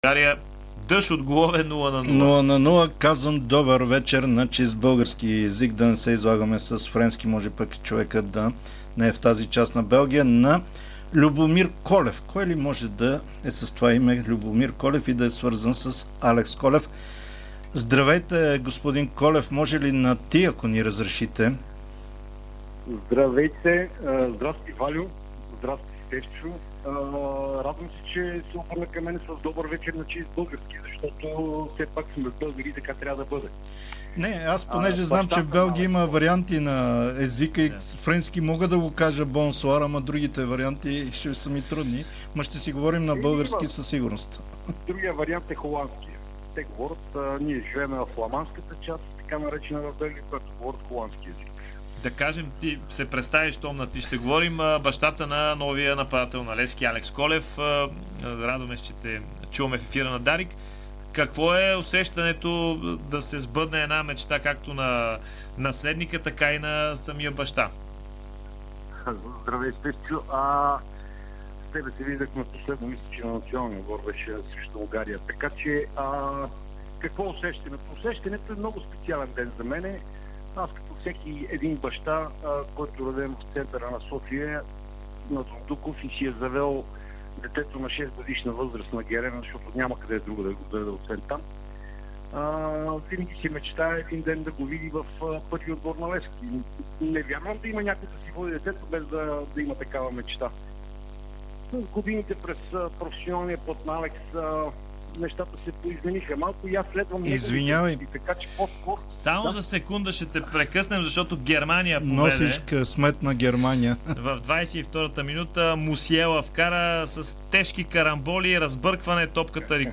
ексклузивно интервю в ефира на Дарик радио